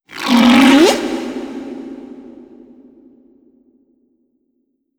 khloCritter_Male08-Verb.wav